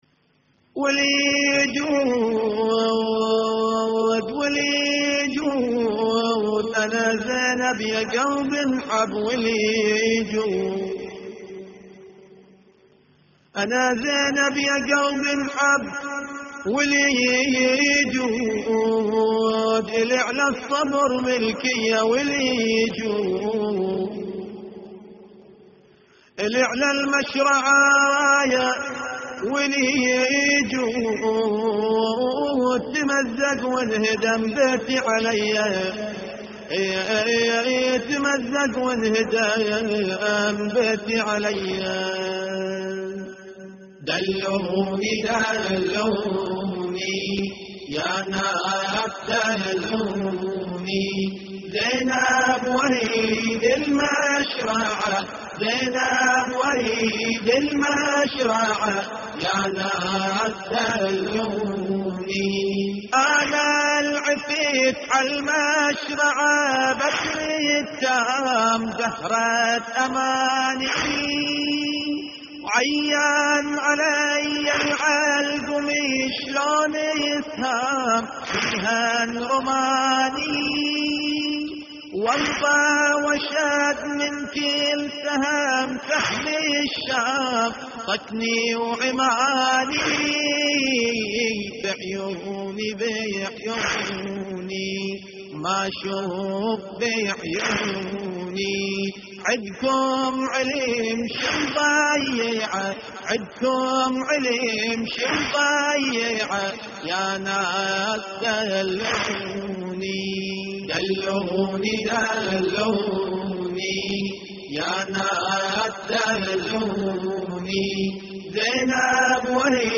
ولي جود انا زينب يا قلبي انحب (نعي) + دلوني زينب واريد المشرعة
اللطميات الحسينية
استديو